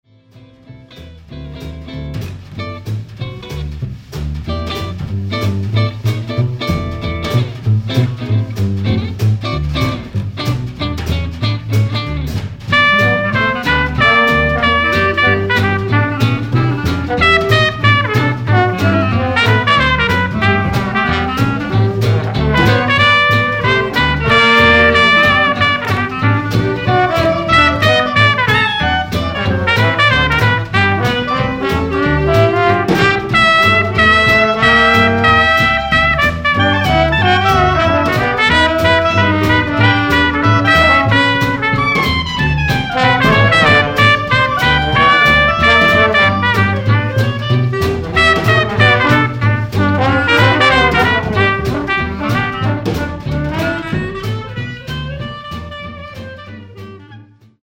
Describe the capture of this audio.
VINTAGE MICROPHONE EXPERIMENTS Studio Recording in mono, 1950’s production values